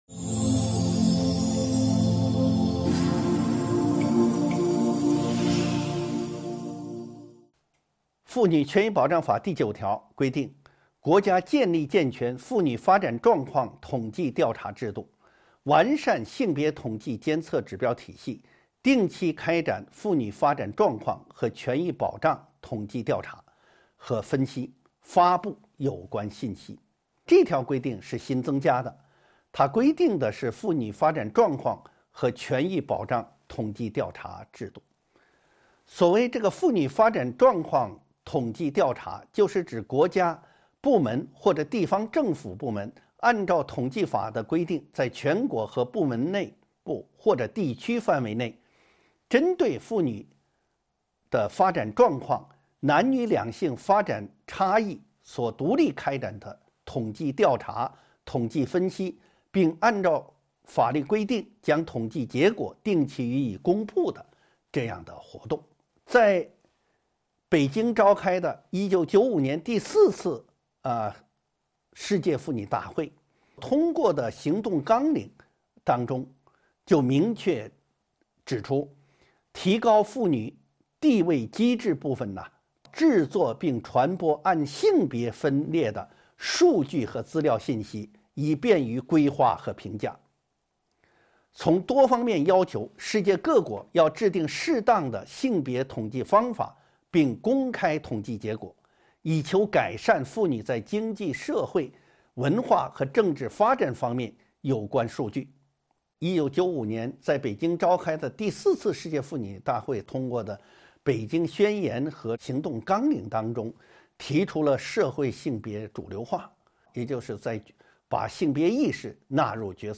音频微课：《中华人民共和国妇女权益保障法》11.分性别调查统计制度